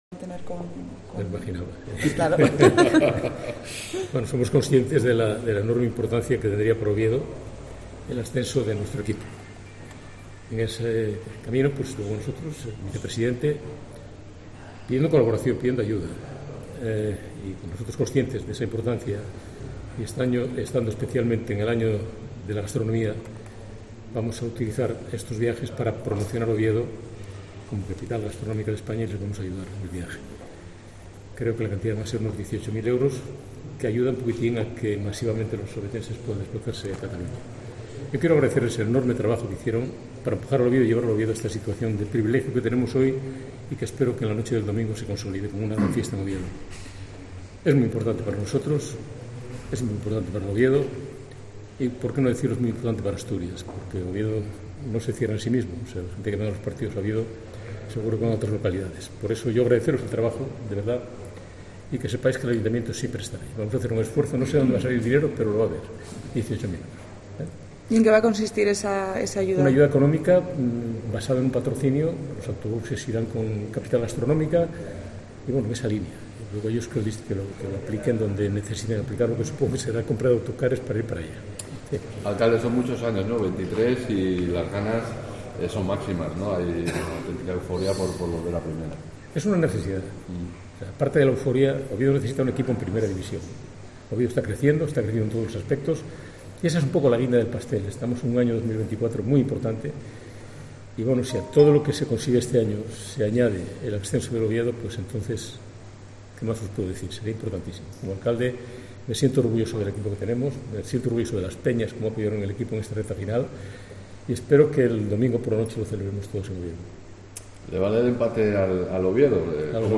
El alcalde de Oviedo, Alfredo Canteli, atendió a los medios de comunicación tras la reunión con la APARO.